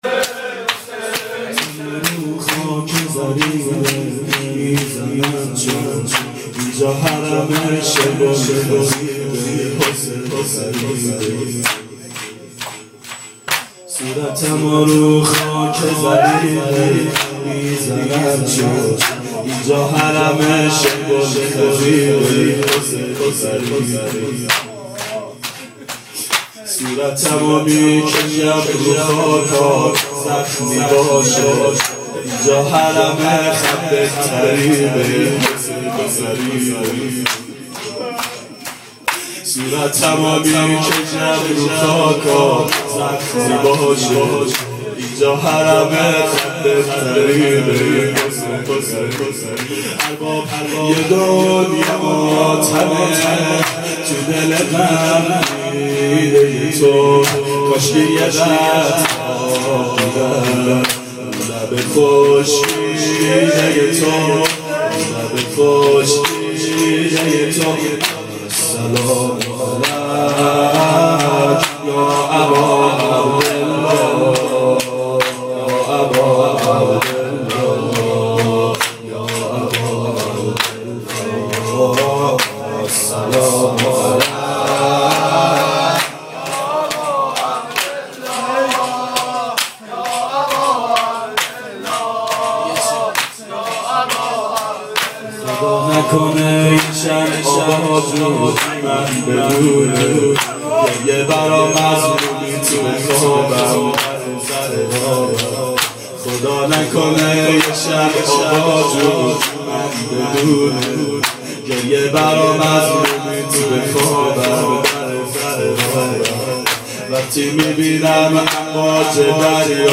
• ظهر اربعین سال 1390 محفل شیفتگان حضرت رقیه سلام الله علیها